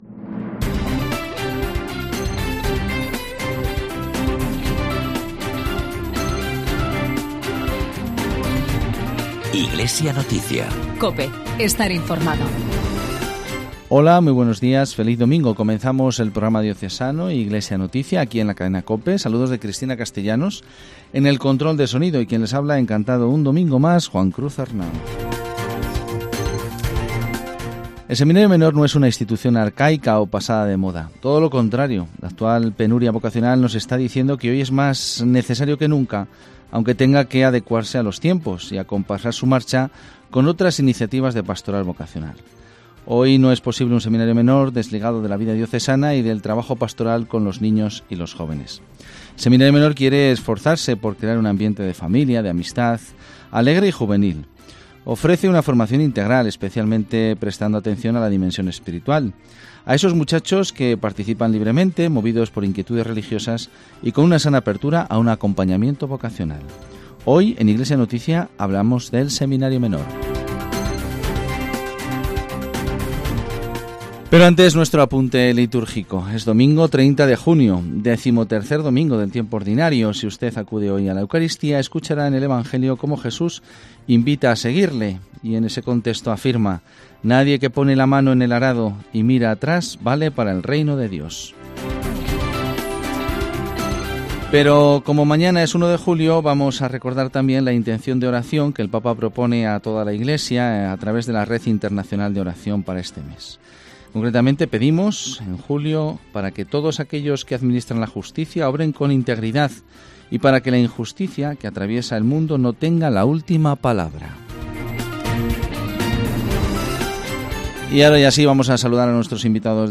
Programa semanal de información cristiana